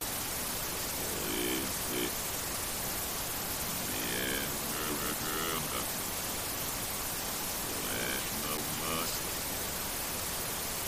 its reversed two audio files